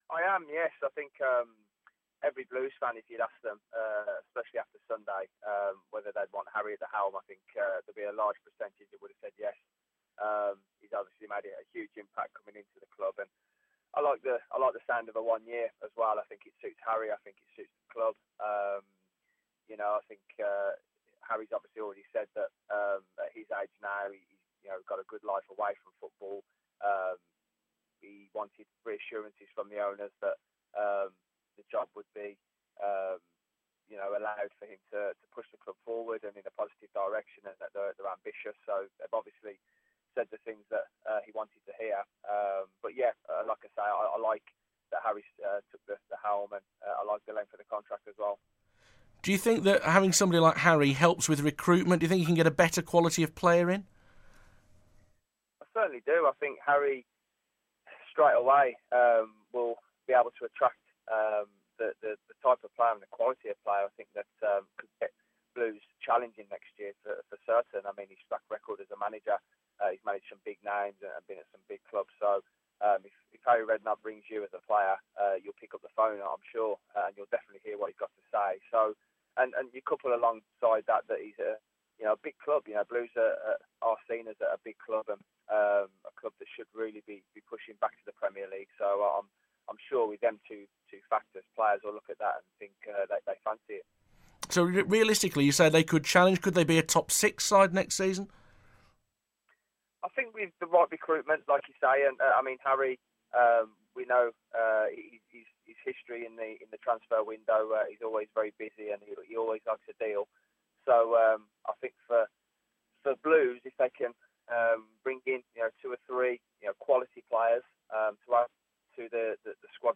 Former Birmingham City midfielder Darren Carter tells BBC WM that Harry Redknapp won't find it hard to recruit players to St Andrew's